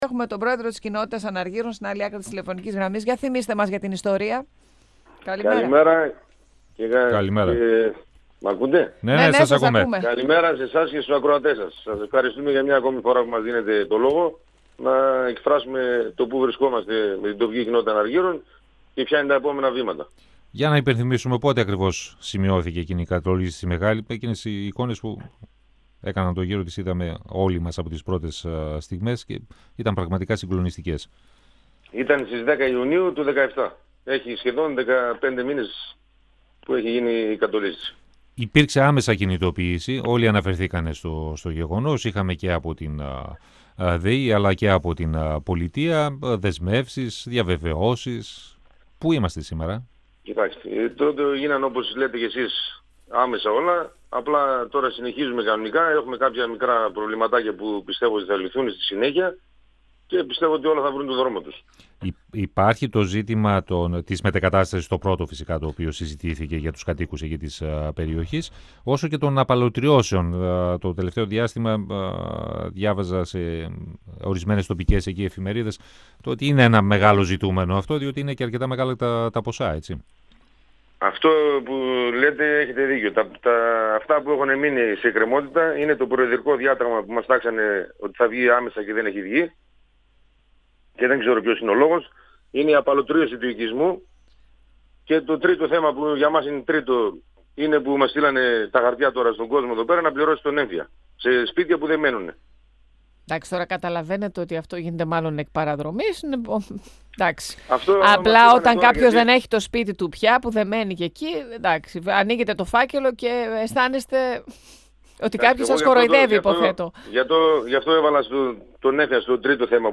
O πρόεδρος της Κοινότητας Αναργύρων Γιώργος Τσισμαλίδης στον 102FM του Ρ.Σ.Μ. της ΕΡΤ3